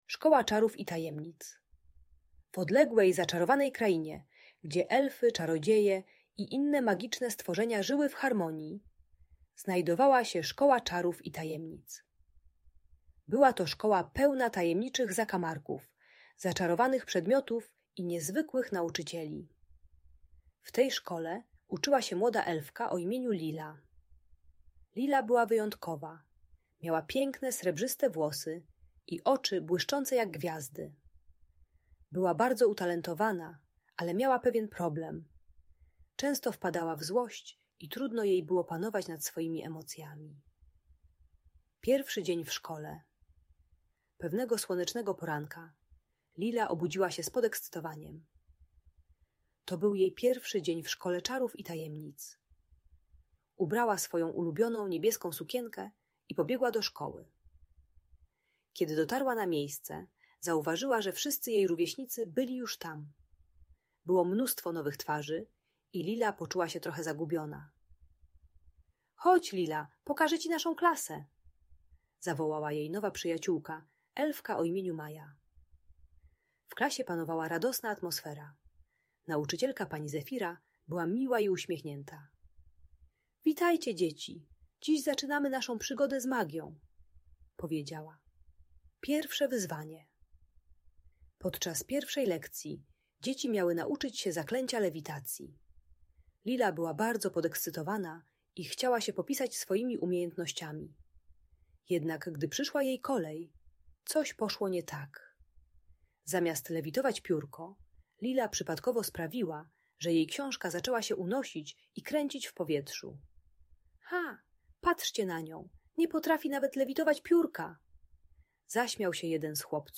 Historia Lili z Szkoły Czarów i Tajemnic - Audiobajka